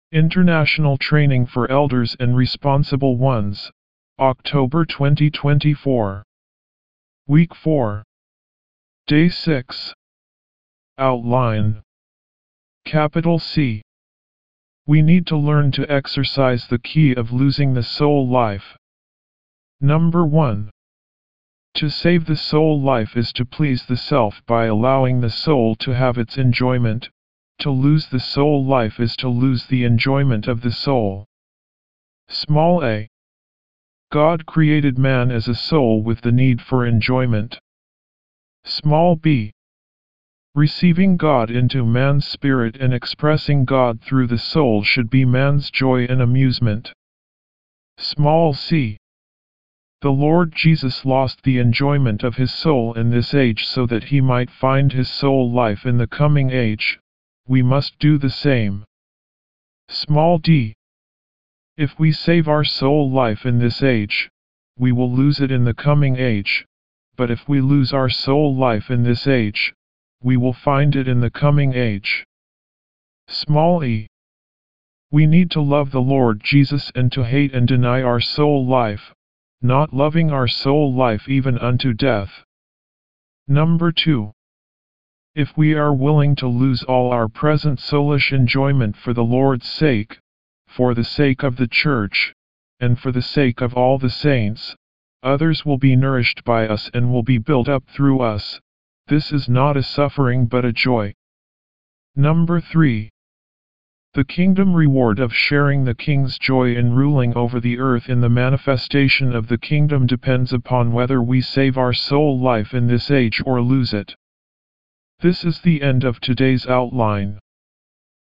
W4  Outline Recite
D6 English Rcite：